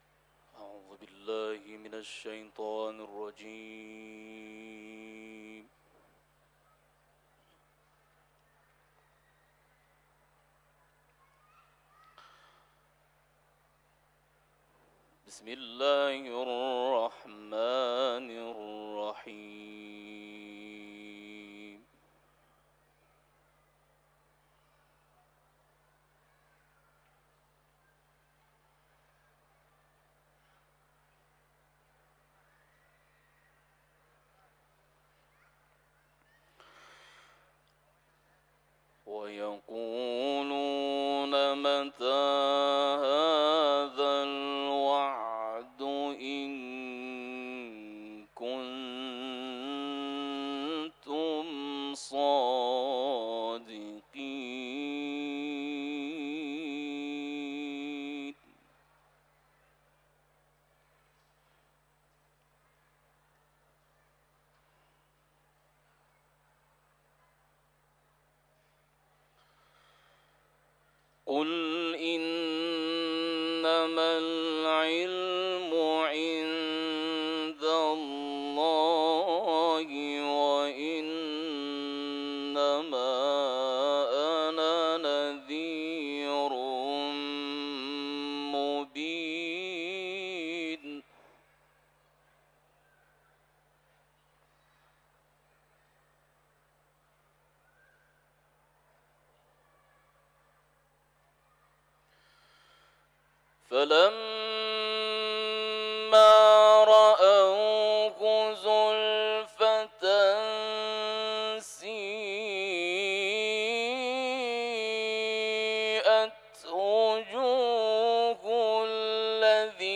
تلاوت
حرم مطهر رضوی ، سوره ملک